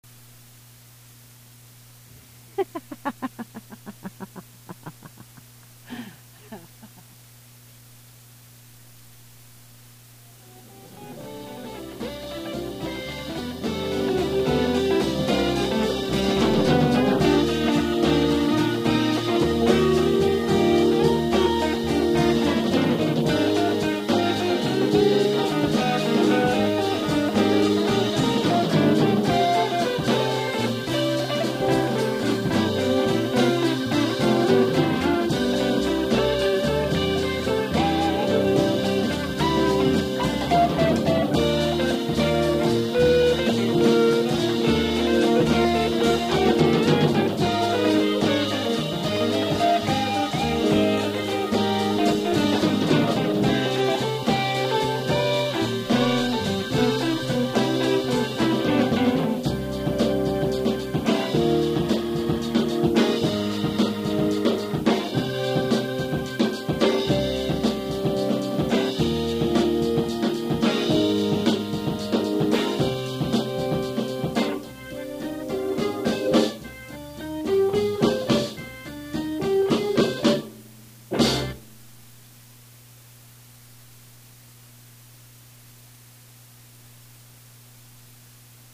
A homegrown slice of Waka/Jawaka anyone?